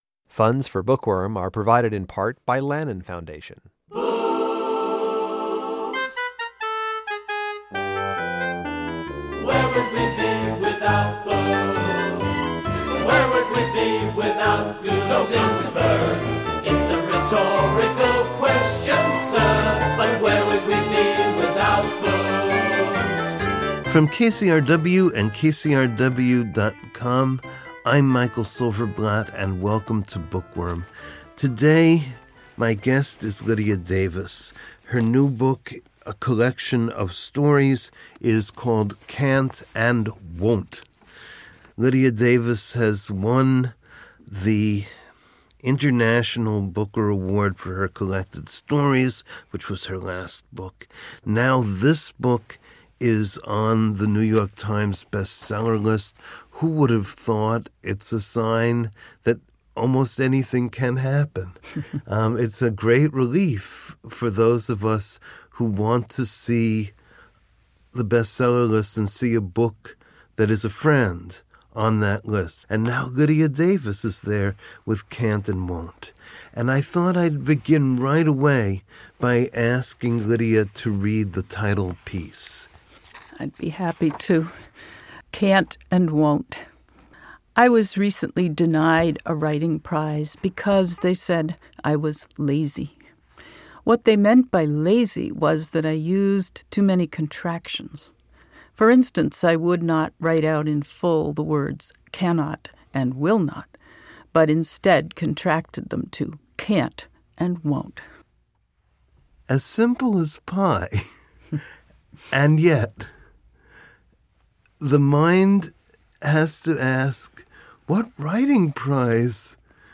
Intellectual, accessible, and provocative literary conversations.